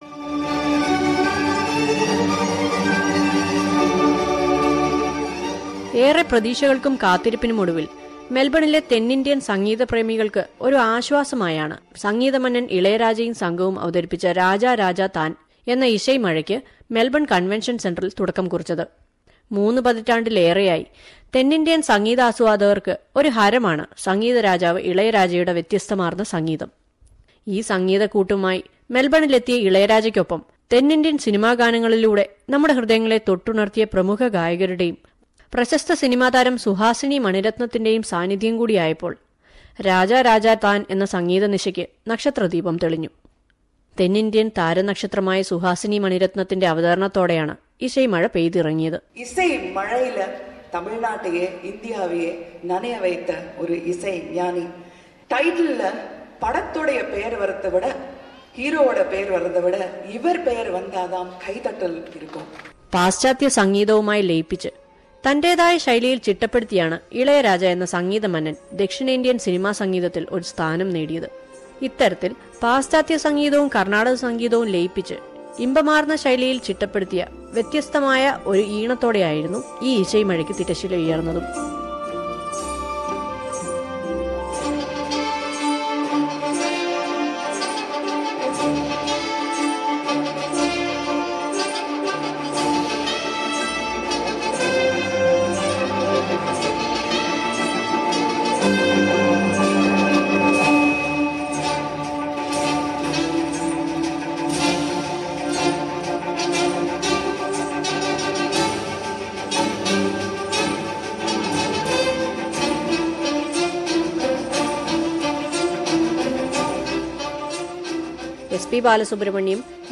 It was by tapping the feet and clapping the hands that the audience enjoyed the musical extravaganza of popular South Indian singers like SP Balasubramaniam, KS Chithra, Madhu Balakrishnan led by the great composer Ilayaraja. Let us listen to the coverage of Raja Raja Thaan the musical night organized in Melbourne.